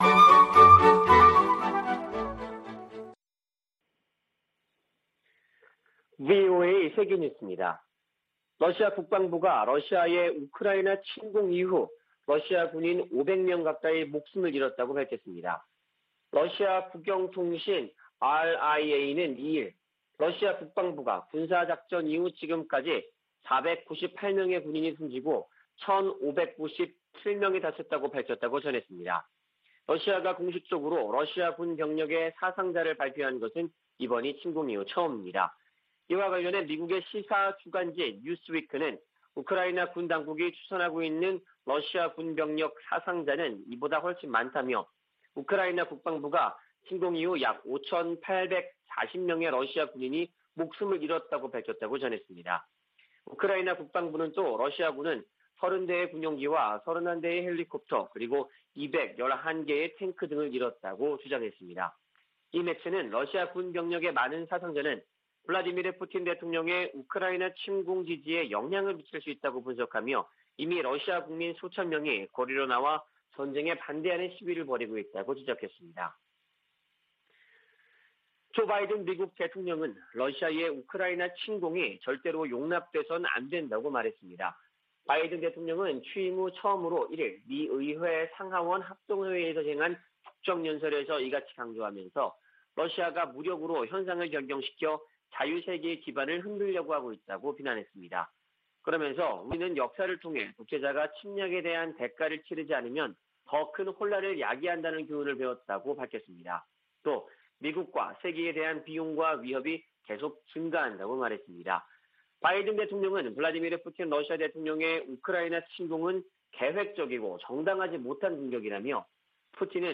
VOA 한국어 아침 뉴스 프로그램 '워싱턴 뉴스 광장' 2022년 3월 3일 방송입니다. 조 바이든 미국 대통령이 취임 후 첫 국정연설에서 러시아의 우크라이나 침공을 강력 비판했습니다.